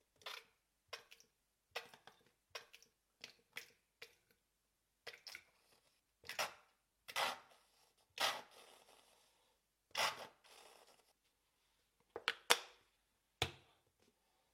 Tiếng Xịt Sốt, Tương Ớt khi ăn uống…
Thể loại: Tiếng ăn uống
Description: Hiệu ứng âm thanh Tiếng xịt sốt, Tiếng xịt tương ớt, Tiếng xịt ketchup mô phỏng âm thanh chân thực khi bóp chai sốt, rưới lên xúc xích, hamburger hay khoai tây chiên. Âm thanh “phụt”, “xèo”, “xịt”, “xì” sống động, gợi cảm giác ngon miệng và chân thật trong cảnh ăn uống.
tieng-xit-sot-tuong-ot-khi-an-uong-www_tiengdong_com.mp3